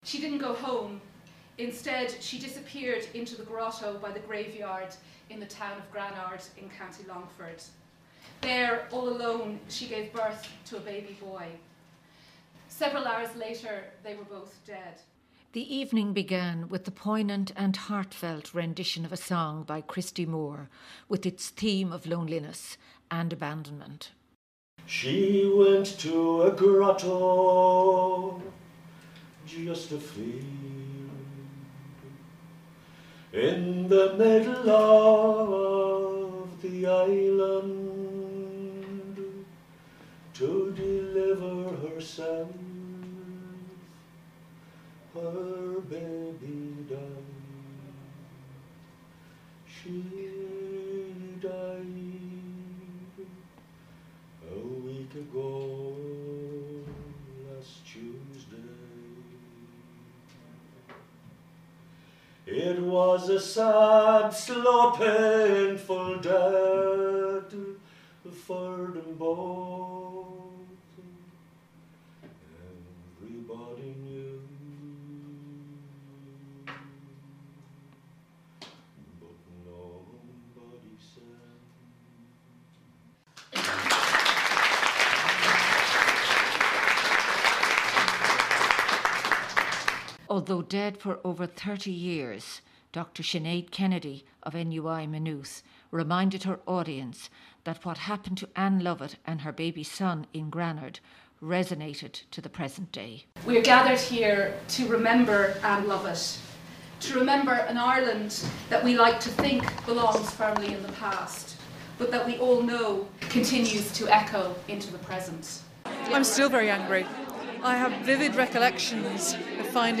Poets, Academics, Journalists and Students with Singer/Songwriter Christy Moore